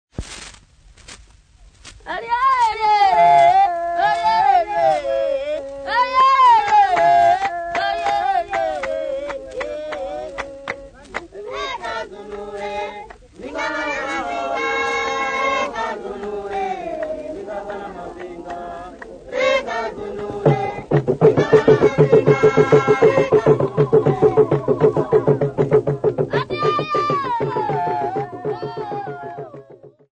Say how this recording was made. Field recordings Africa Tanzania city not specified f-tz